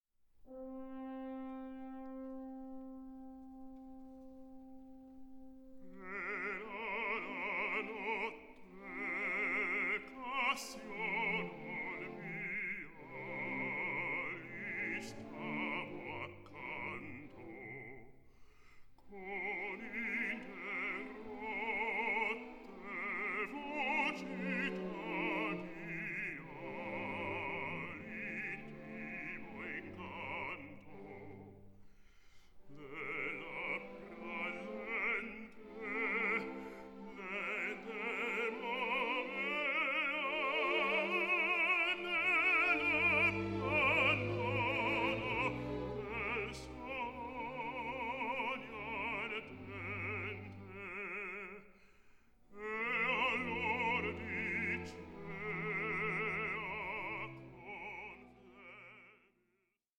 ambitious studio recording
Austrian tenor
American baritone